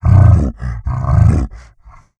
MONSTERS_CREATURES
MONSTER_Exhausted_10_mono.wav